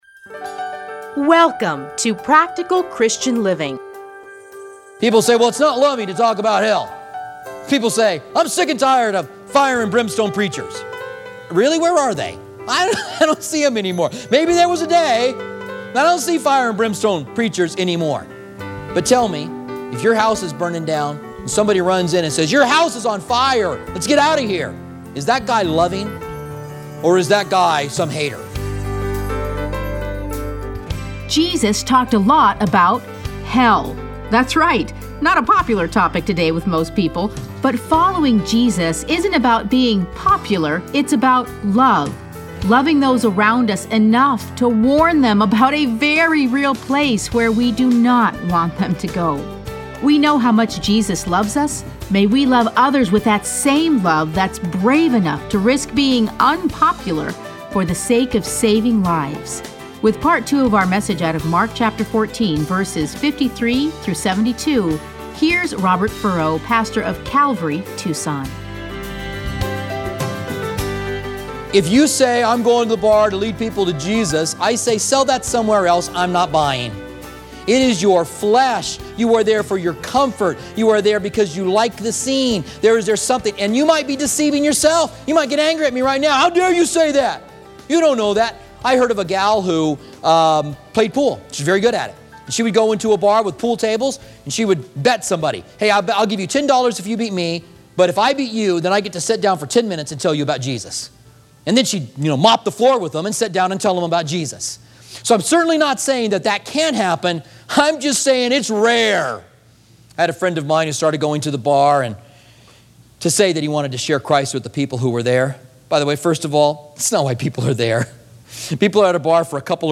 Listen to a teaching from Mark 14:53-72.